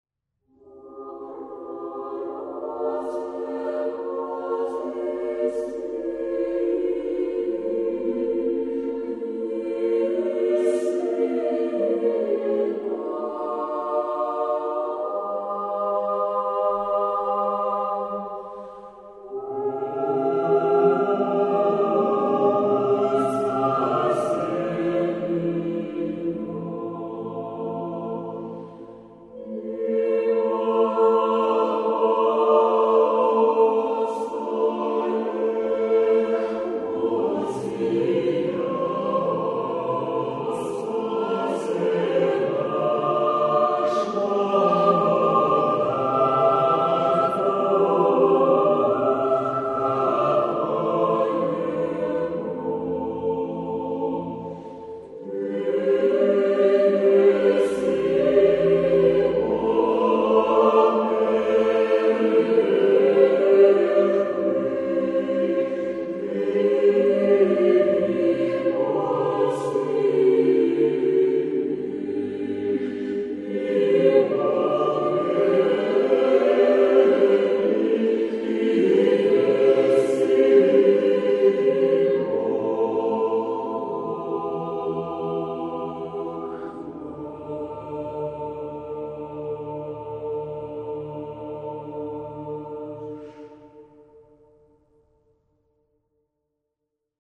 Troitsa_Stihiryi_na_litii-cca760.mp3